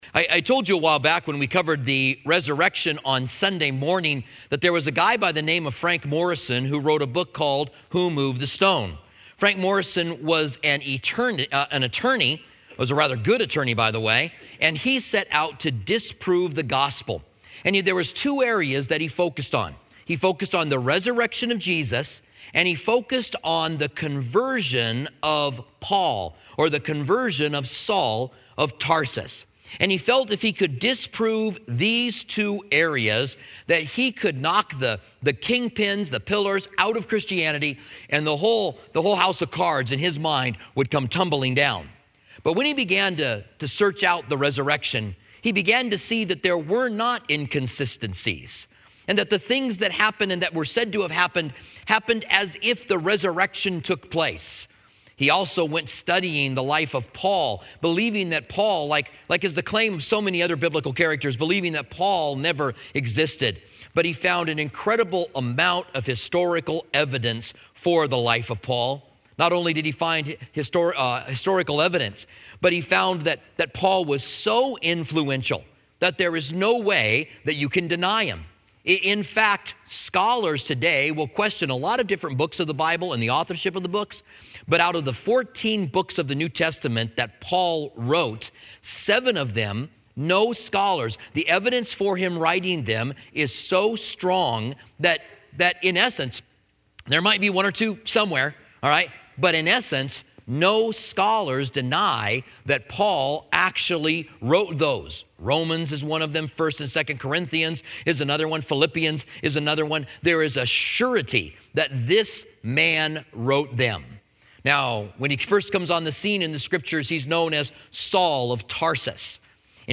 Commentary on Acts